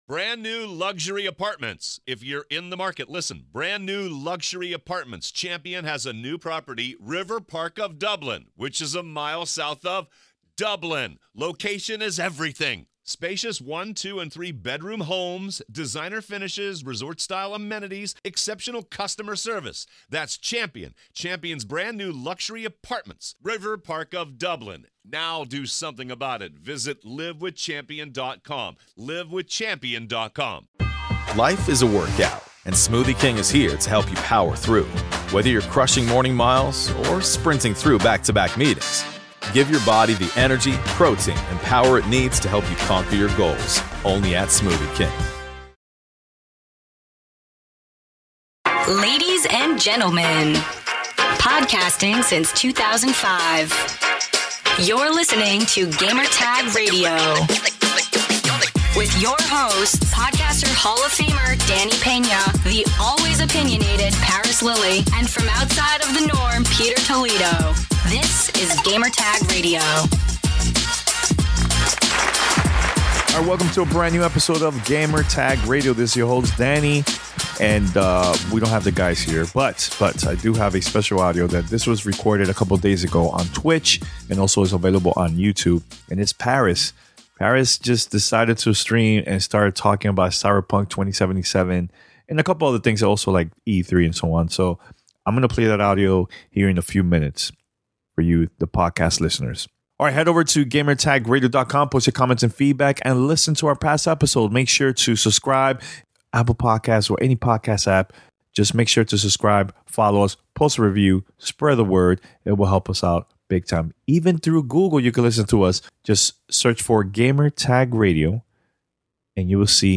50 minute discussion live on Twitch about Cyberpunk 2077 at E3, what is next for Destiny, PS5, Xbox, Nintendo, Uncharted 5 and more!